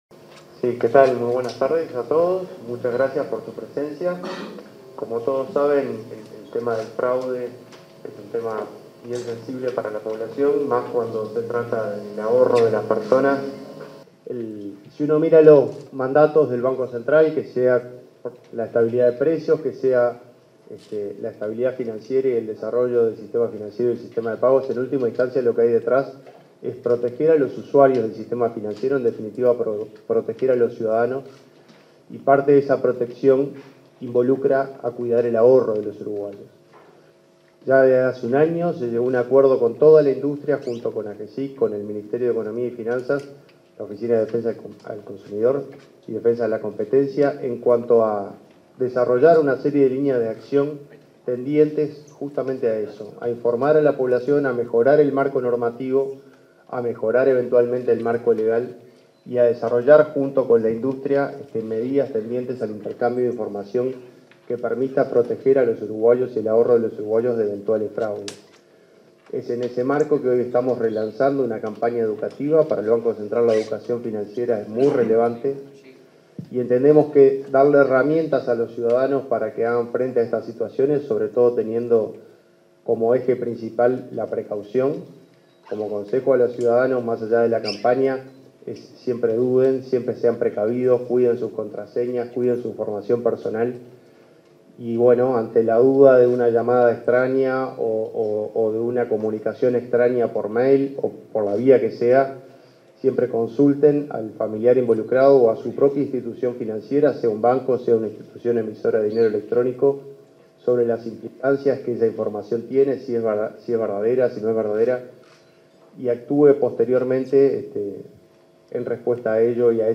Palabras del presidente del BCU, Washington Ribeiro
En el marco de la presentación de la campaña educativa “Que no te defrauden”, para la prevención de delitos y fraudes contra los usuarios del sistema